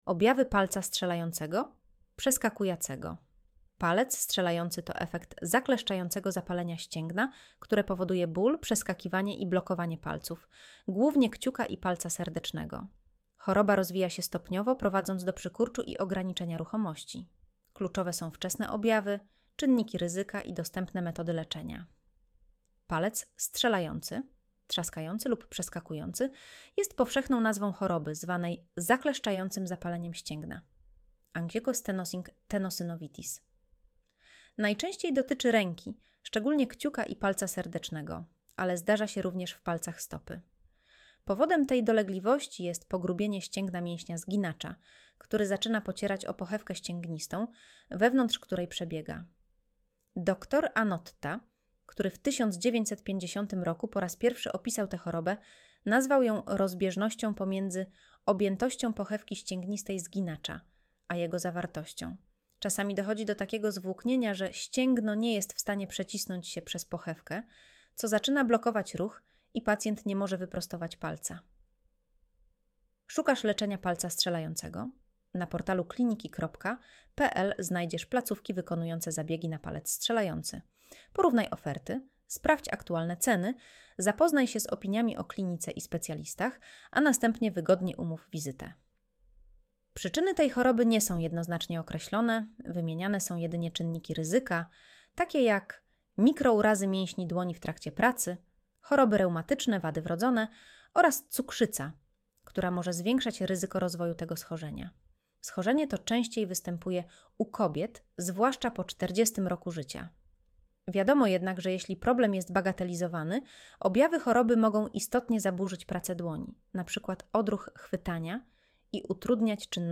Słuchaj artykułu Audio wygenerowane przez AI, może zawierać błędy